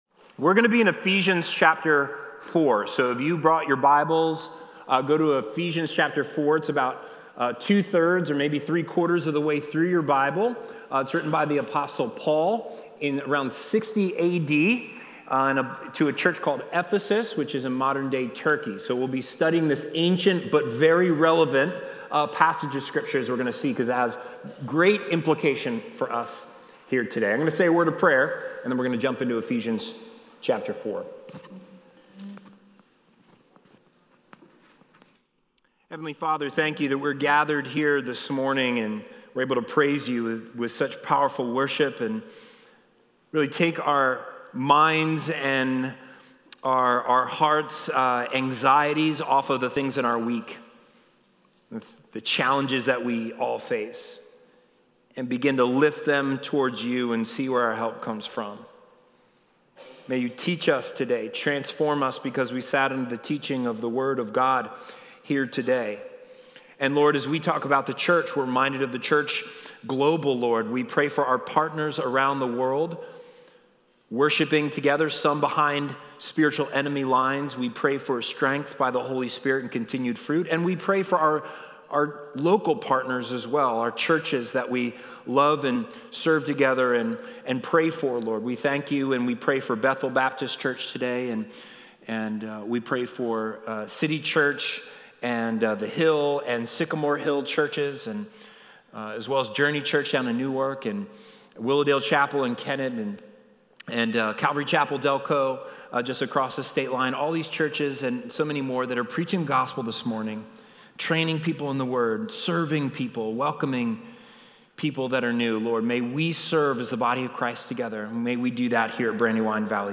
Sermon Archives - Brandywine Valley Church
Watch previously recorded Sunday sermons.